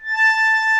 A5 ACCORDI-R.wav